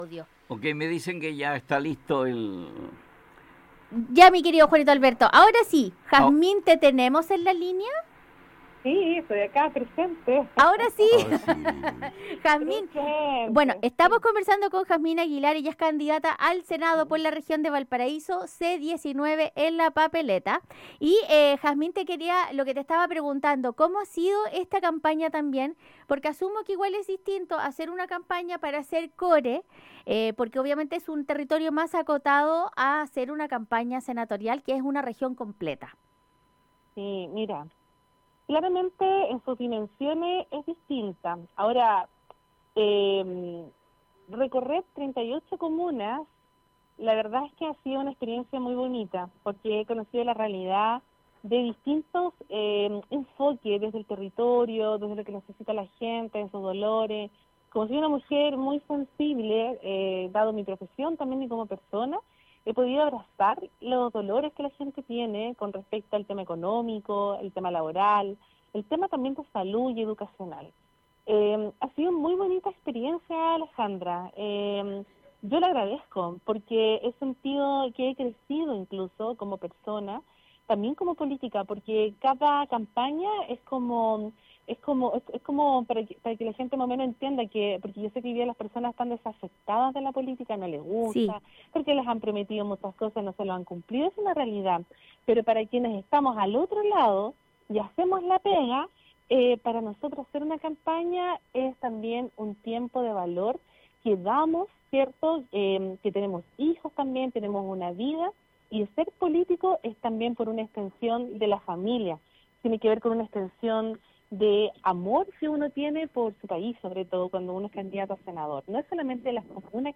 La psicóloga y ex Core contó detalles del porque se presenta como candidata al senado de la región de Valparaíso